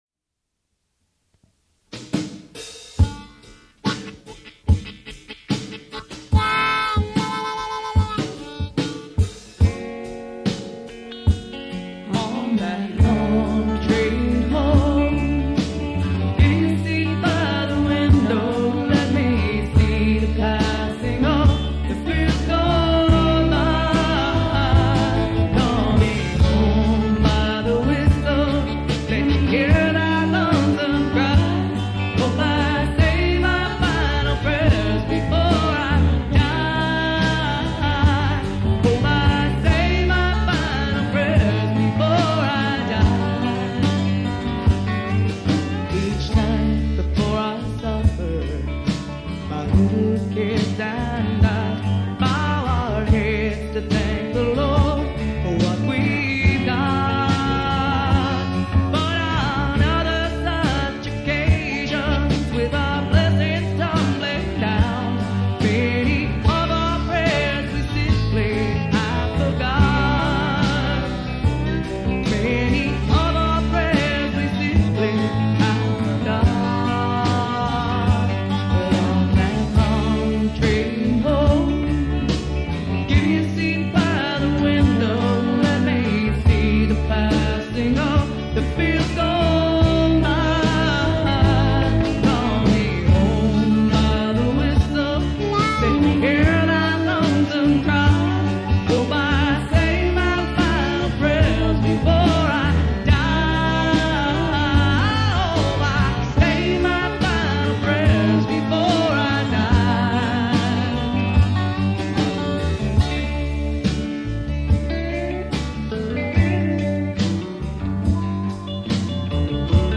NY-based alt. country group fronted by two female singers.
harmonica
dobro
electric guitar
electric bass
drums